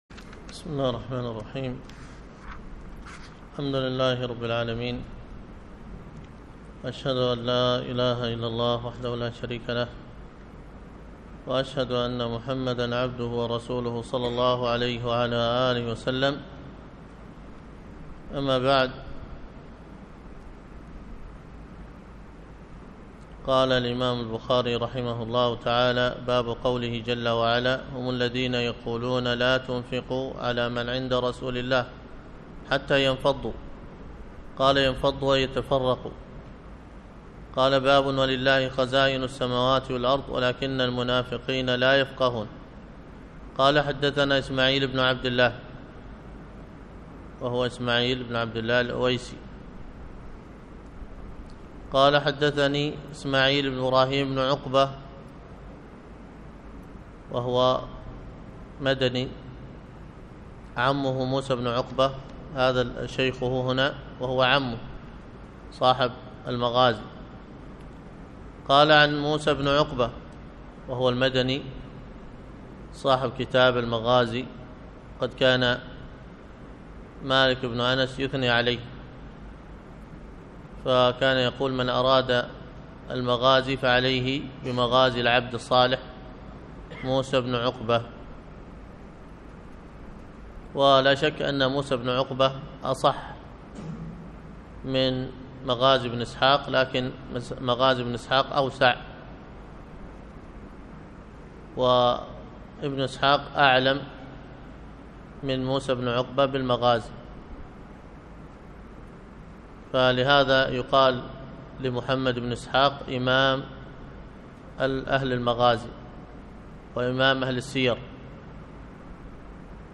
الدرس في كتاب التفسير من صحيح البخاري 236